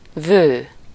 Ääntäminen
Synonyymit beau-fils Ääntäminen France: IPA: /ʒɑ̃dʁ/ Haettu sana löytyi näillä lähdekielillä: ranska Käännös Ääninäyte 1. vő Suku: m .